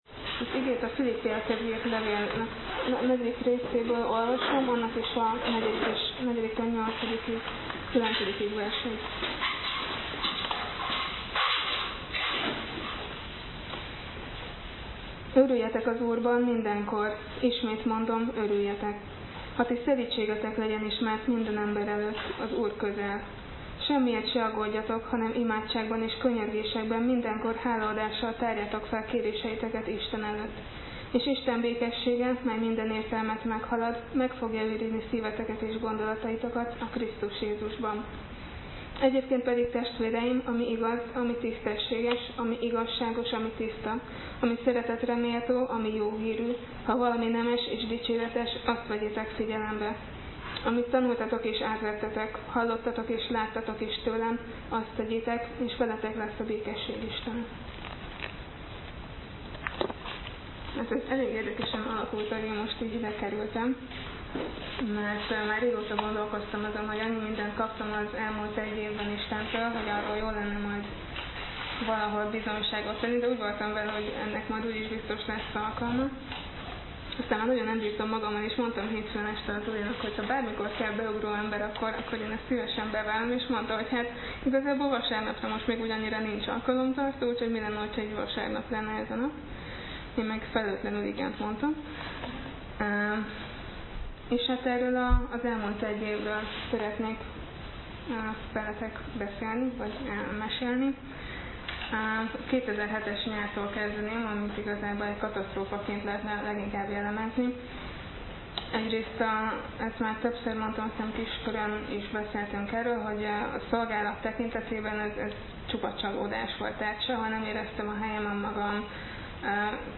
Bizonyságtétel